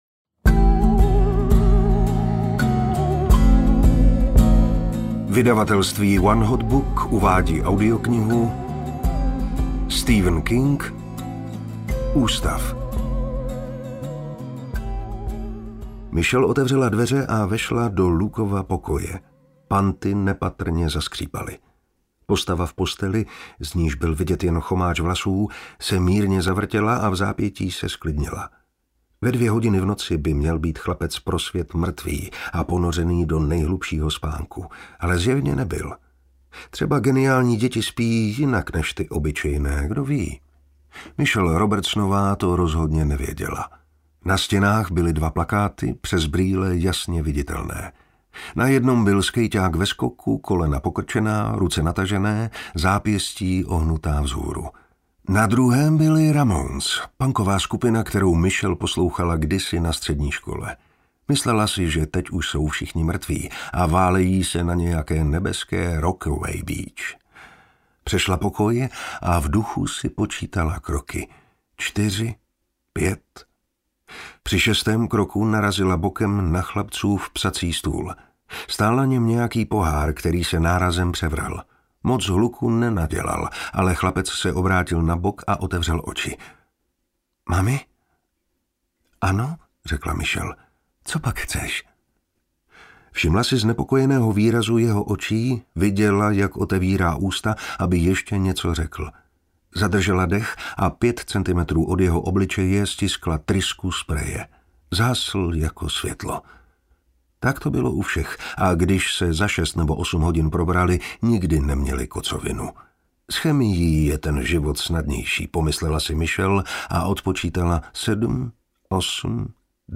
Ústav audiokniha
Ukázka z knihy